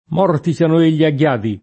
morto [m0rto] part. pass. di morire, agg., s. m. — anche elem. di toponimi: Mar M., Lago M. — possibile l’elis. dell’-o nell’antica locuz. morto a ghiado (in frasi d’ingiuria o di minaccia): che sie mort’a ghiado [ke SSie m0rt a ggL#do] (Sacchetti); che siate mort’a ghiado [ke SSL#te m0rt a ggL#do] (id.); possibile del resto anche una concordanza a senso di morti (pl.) con ghiadi (pl.): morti siano egli a ghiadi [